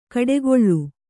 ♪ kaḍegoḷḷu